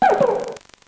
Cri de Polarhume dans Pokémon Noir et Blanc.